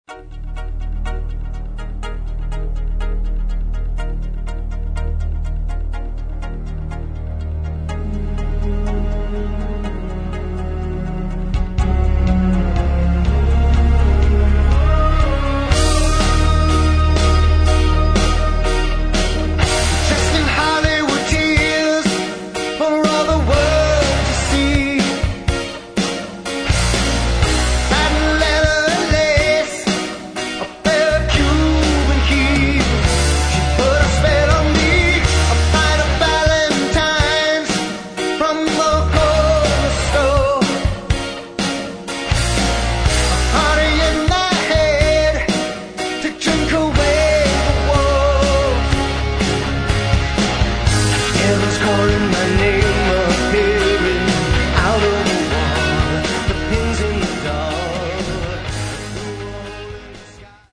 Рок
Альбом очень интересный, прекрасно записаный, отличный звук.